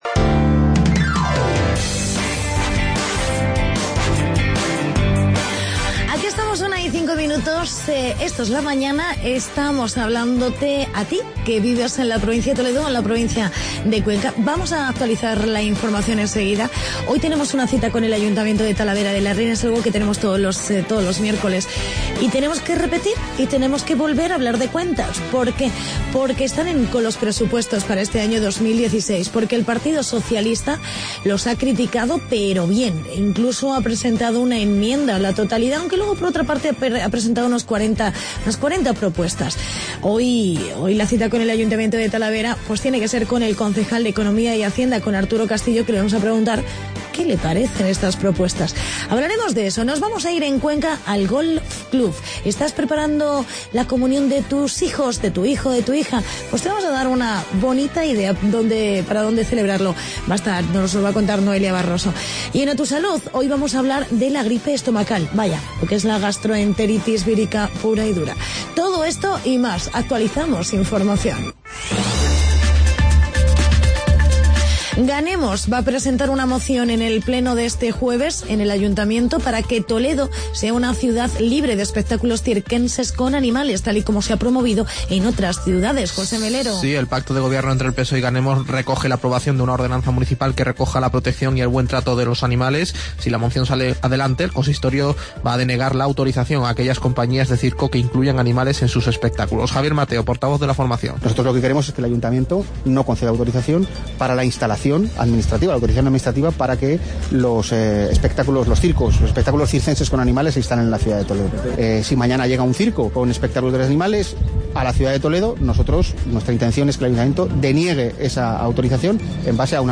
Entrevista con el concejal Arturo Castillo y en "A Tu Salud" hablamos de gripe estomacal.